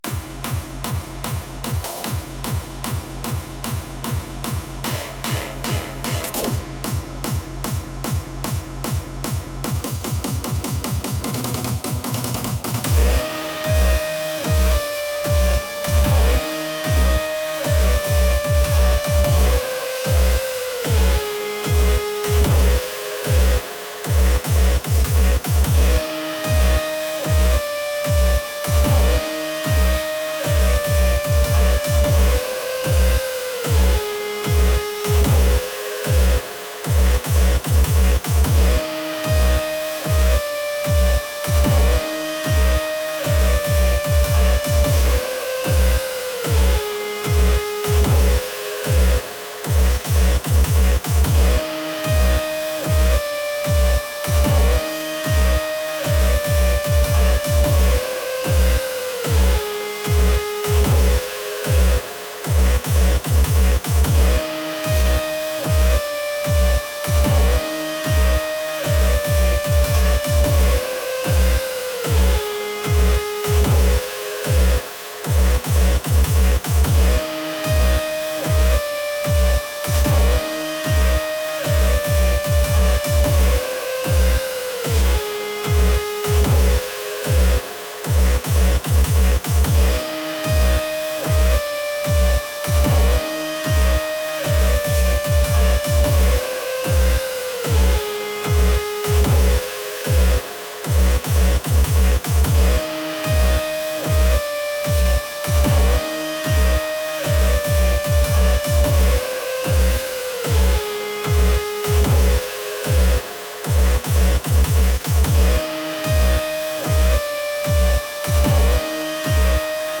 energetic | intense